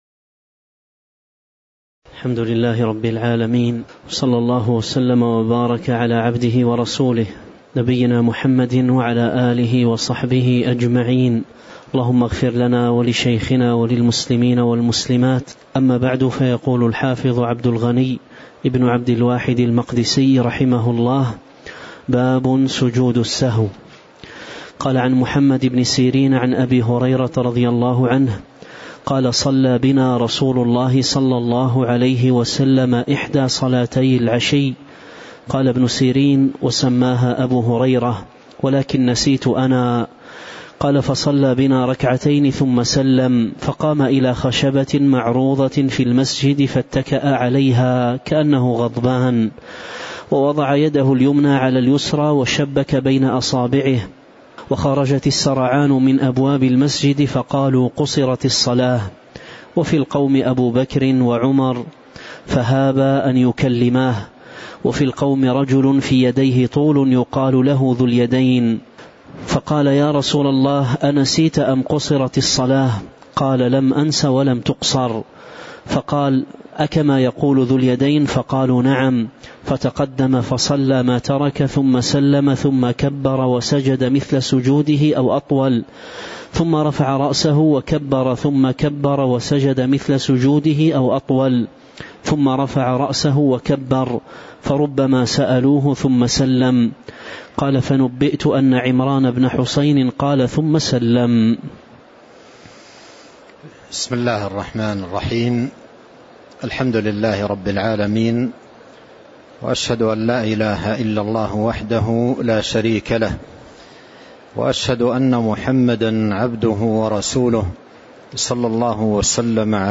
تاريخ النشر ٩ ربيع الثاني ١٤٤٤ هـ المكان: المسجد النبوي الشيخ